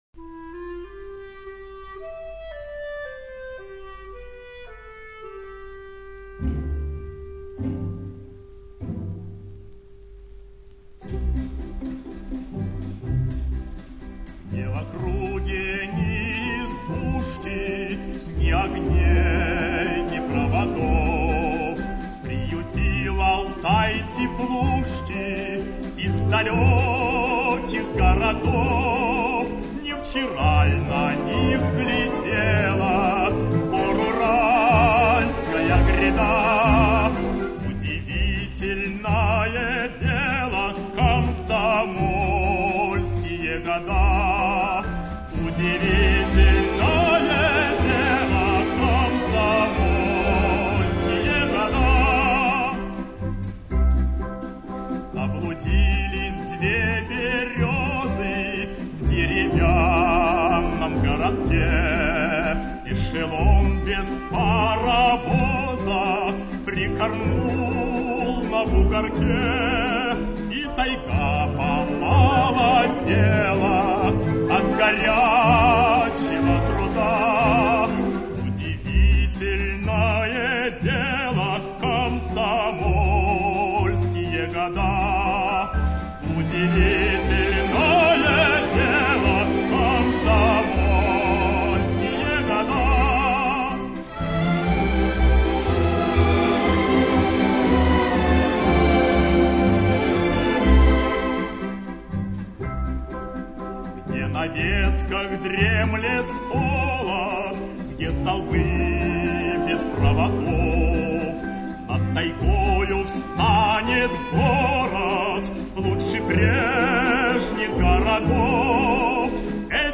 Песня о молодости, проникнутая комсомольской романтикой.